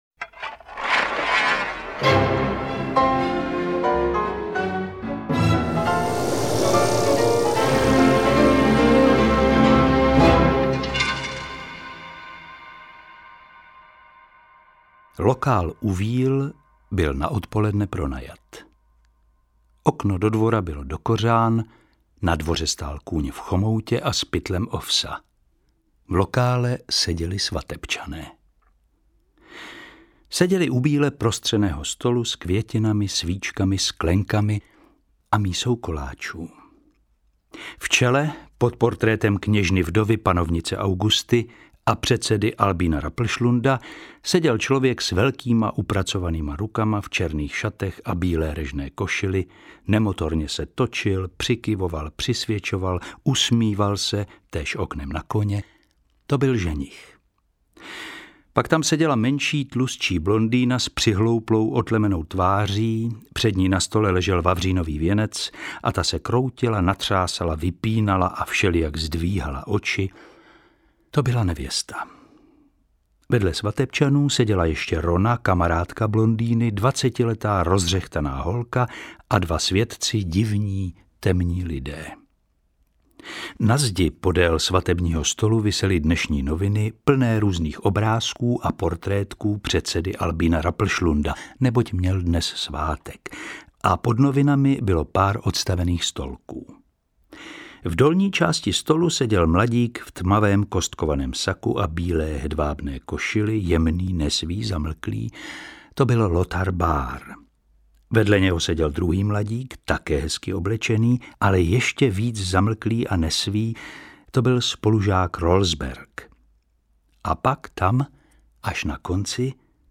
Interpret:  Jiří Ornest
AudioKniha ke stažení, 12 x mp3, délka 5 hod. 14 min., velikost 574,4 MB, česky